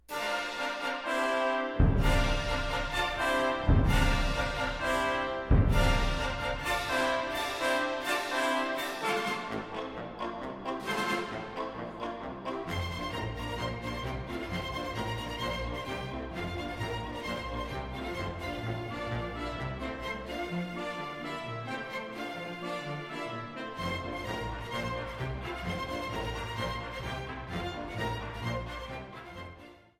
thrilling orchestral music from classic movies